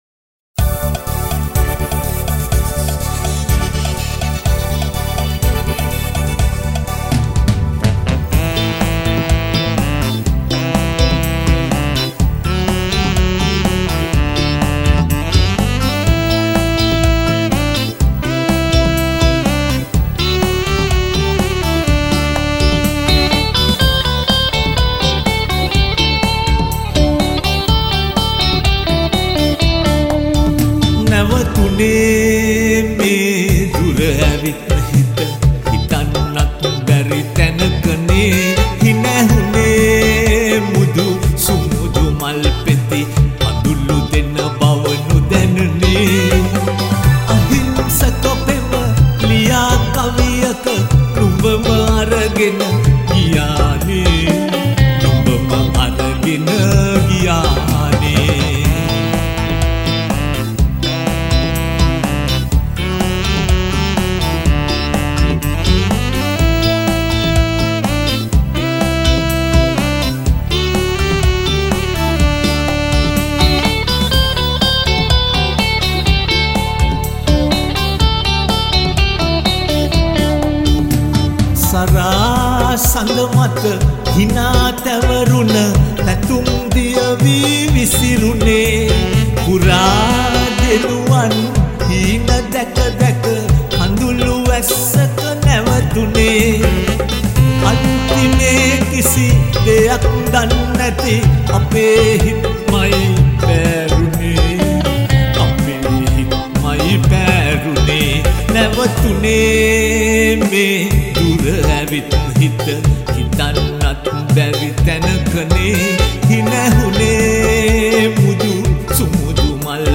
original songs